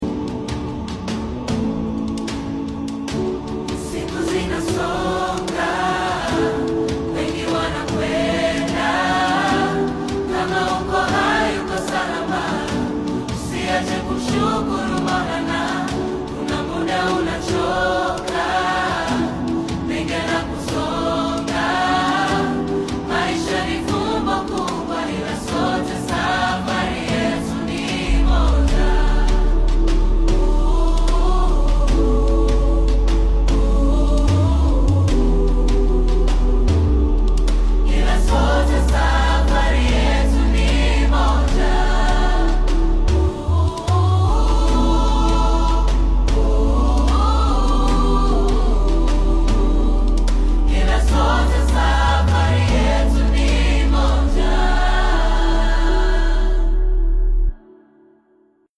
Nice chords and voices